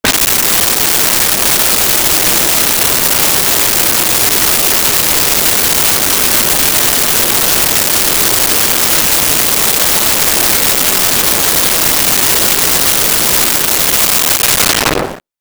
Crowd Before A Show
Crowd Before a Show.wav